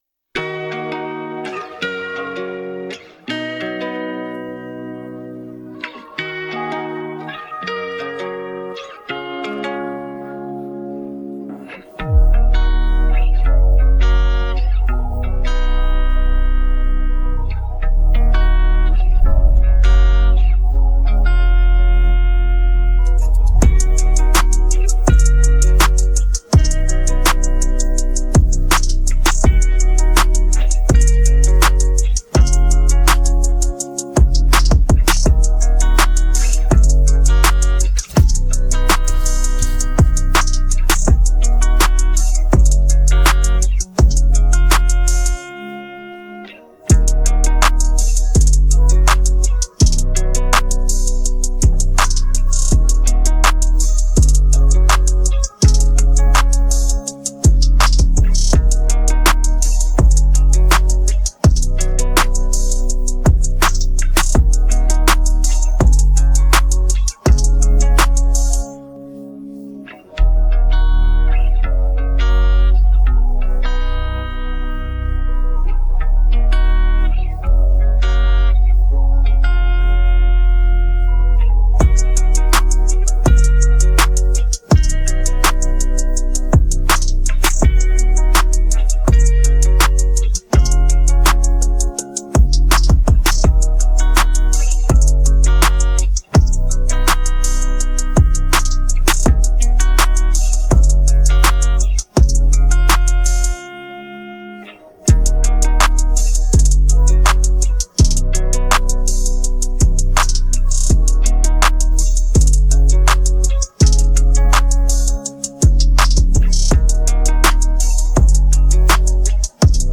Hip hophiphop trap beats